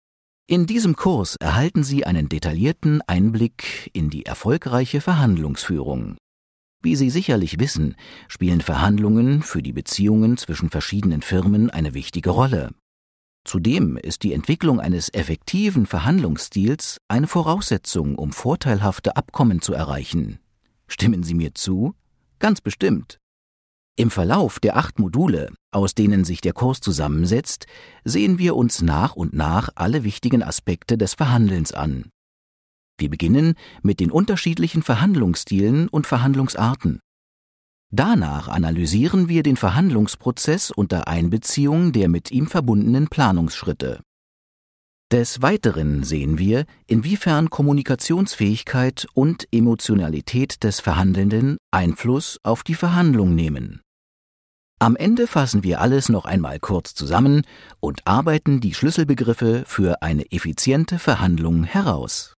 Seinen Schauspielunterricht erhielt er dann später an der Berliner Schauspielschule "Etage - Schule für die darstellenden Künste".*** Stimmlage und Sprechalter: Mittelkräftig, sanft, seriös, voll, warm, weich, 30 bis 45 Jahre.*** Im Synchronbereich hörte man ihn in Produktionen wie dem ZDF Spielfilm "Ferien für eine Woche", der ARD Serie "Hôtel de police", 2 Staffeln in der ARD Serie "Papa ist der Größte", dem Kinofilm "Camorra" und "Unknown Identity".
Sprechprobe: eLearning (Muttersprache):